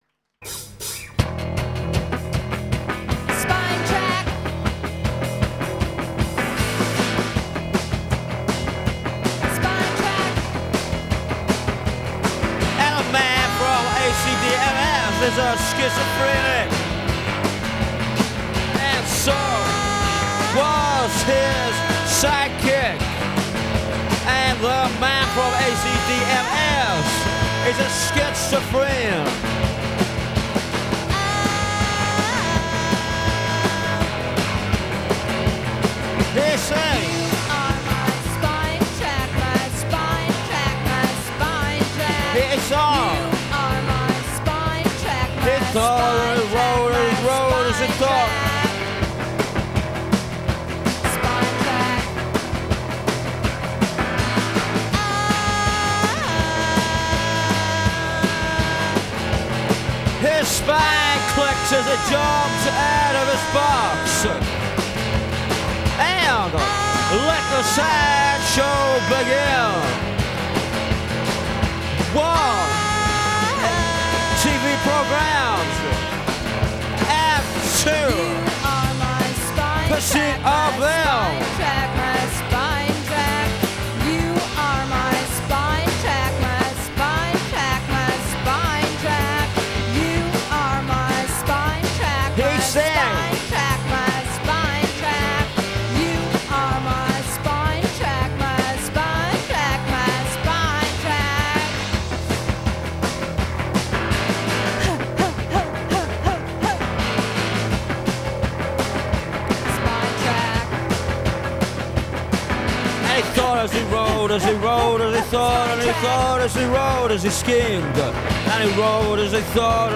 in session backstage at Roskilde festival
recorded at the 1996 Roskilde Festival